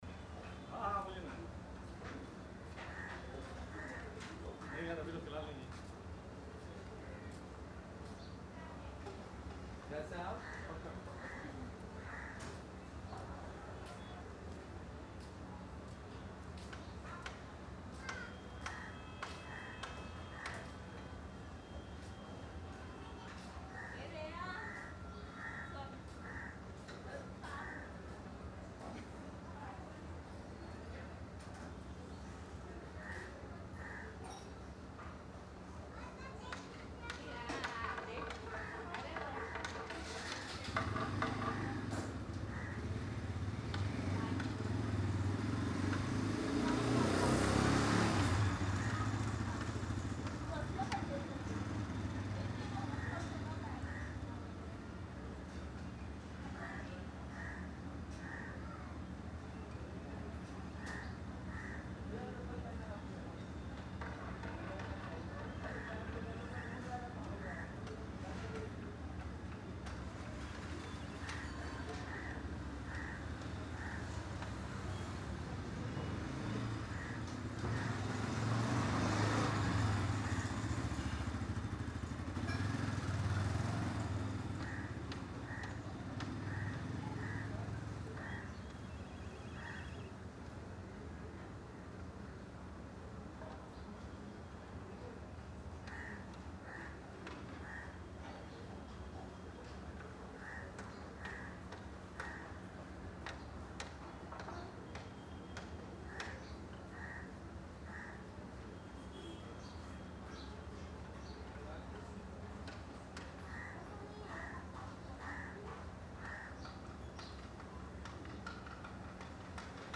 Soft, non-distracting morning nature tone
This ambience captures the calm, refreshing atmosphere of an early morning setting. Soft cool wind, gentle bird chirping, distant traffic hum, light footsteps, and natural outdoor movement blend together to create a peaceful morning soundscape.
You can feel the quiet start of the day—subtle human activity in the distance, occasional bicycle or scooter passing, leaves rustling, and a natural sense of freshness. The ambience is soothing, warm, and perfect for creating a new-day, hopeful, peaceful mood.
Type: Morning Nature + Light Urban Ambience
Fresh, Calm, Peaceful